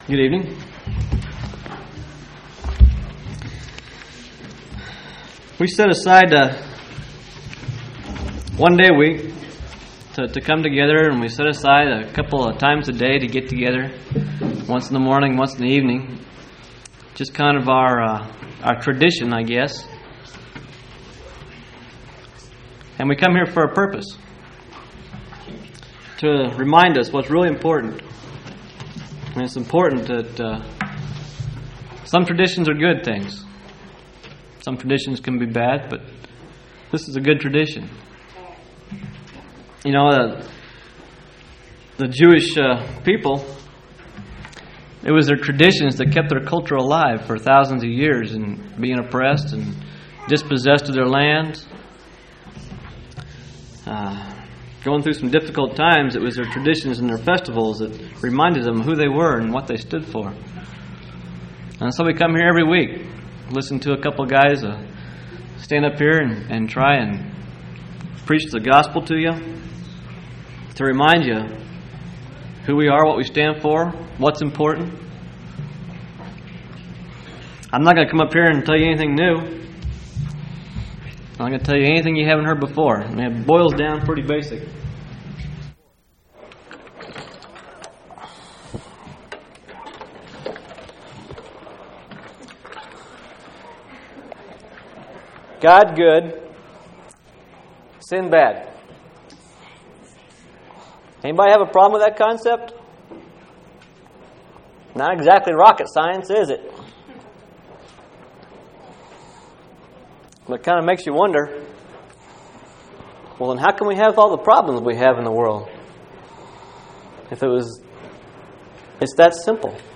10/18/1998 Location: Phoenix Local Event